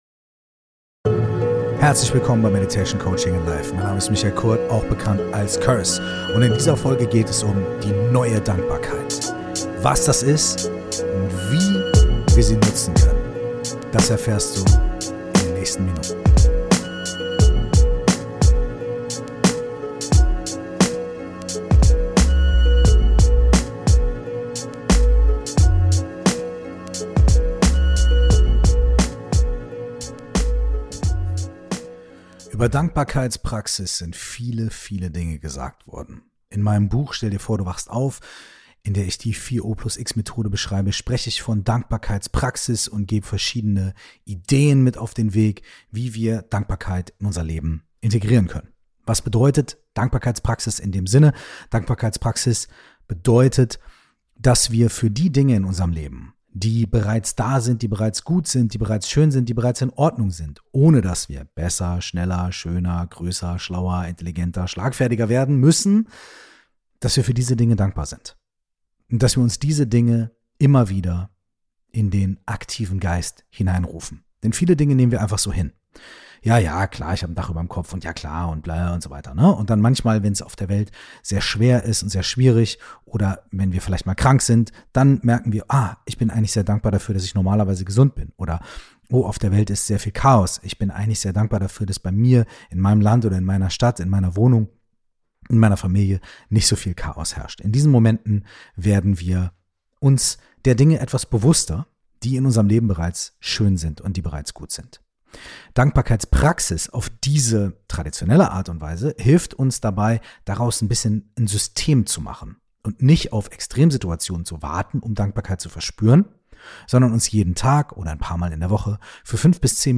Curse stellt die gängige "Dankbarkeitspraxis" auf den Kopf und teilt in dieser Folge seinen neuen Umgang mit Dankbarkeit und leitet eine geführte Meditation dazu an!